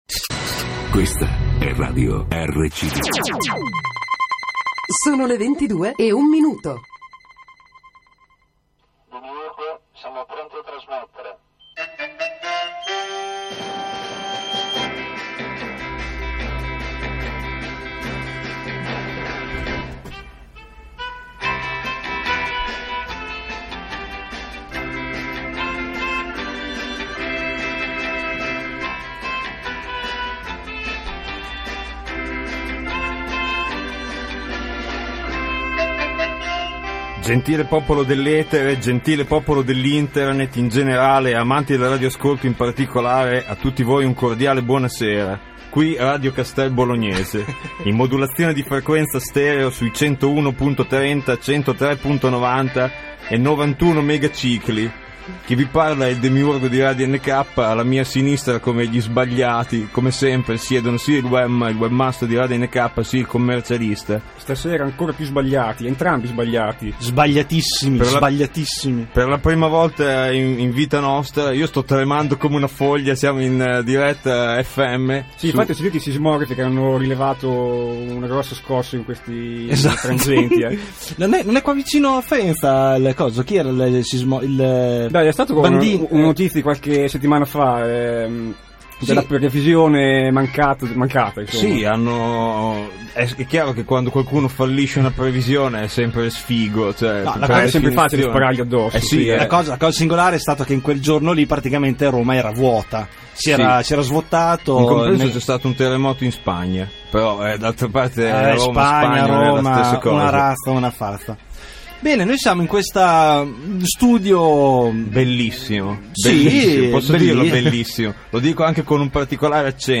Registrata e trasmessa il 31 maggio 2011 Presso gli studi di Radio RCB in Castelbolognese (RA)
Ci siamo realmente seduti nello studio #1 di una nota radio FM locale, e lì abbiamo fatto la radio nk come se niente fosse.